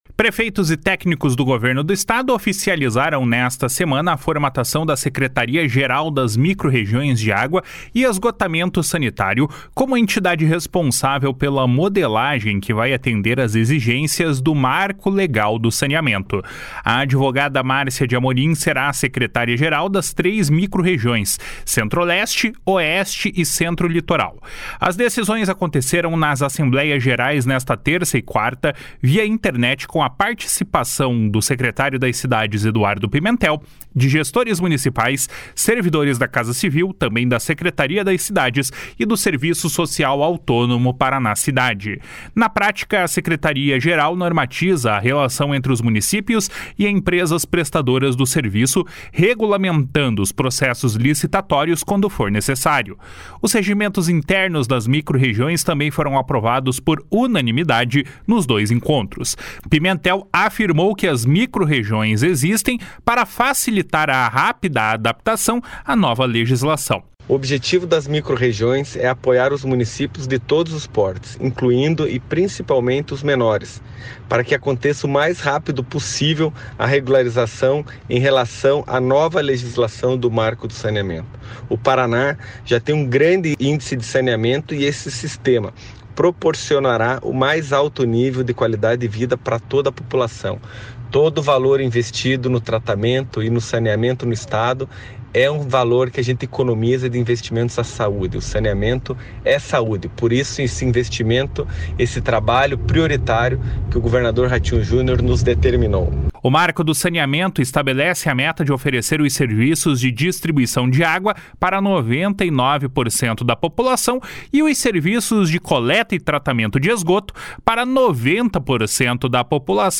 Pimentel afirmou que as microrregiões existem para facilitar a rápida adaptação à nova legislação. // SONORA EDUARDO PIMENTEL //